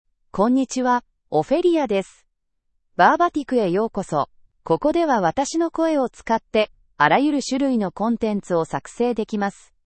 OpheliaFemale Japanese AI voice
Ophelia is a female AI voice for Japanese (Japan).
Voice sample
Listen to Ophelia's female Japanese voice.
Ophelia delivers clear pronunciation with authentic Japan Japanese intonation, making your content sound professionally produced.